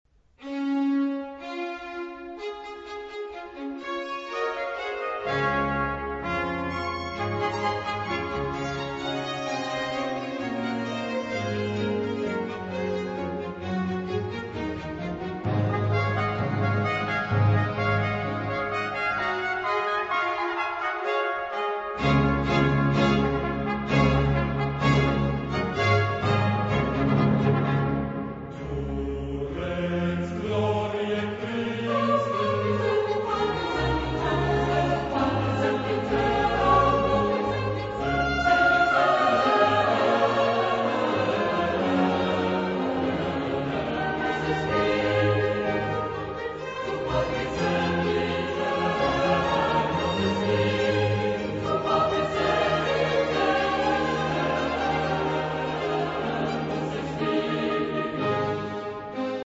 Genre-Style-Form: Hymn (sacred) ; Sacred
Mood of the piece: festive
Type of Choir: SATB  (4 mixed voices )
Instrumentation: Orchestra  (11 instrumental part(s))
Tonality: D major